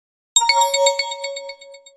Category: HTC Ringtones